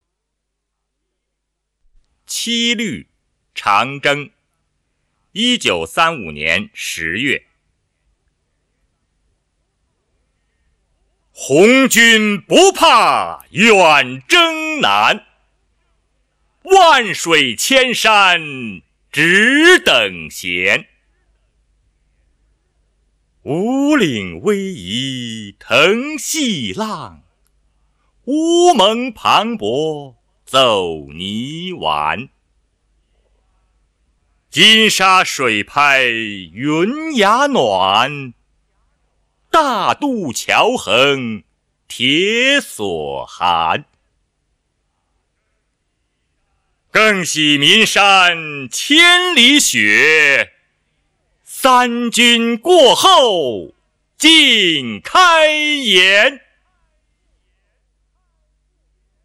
毛泽东《七律·长征》原文和译文及赏析（含朗读）